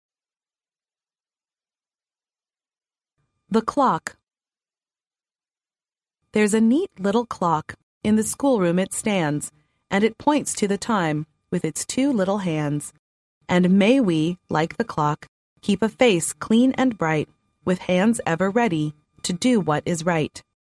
幼儿英语童谣朗读 第49期:时钟 听力文件下载—在线英语听力室